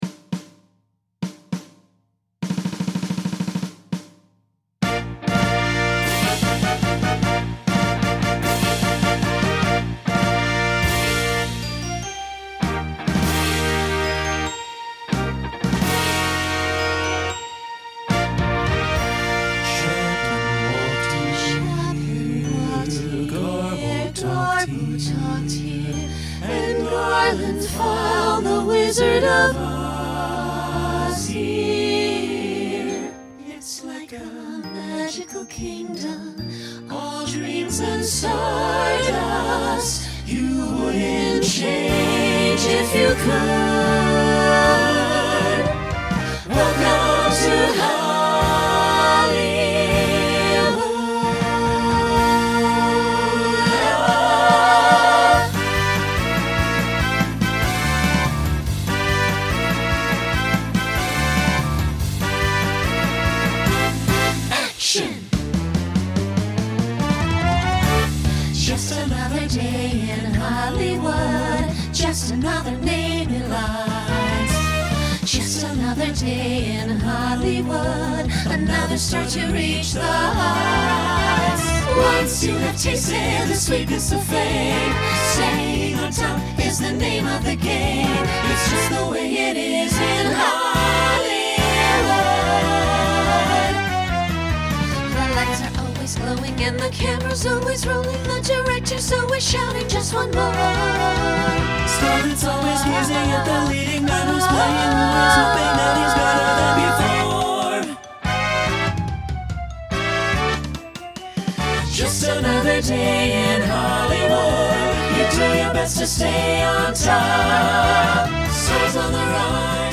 Instrumental combo Genre Broadway/Film
Voicing SATB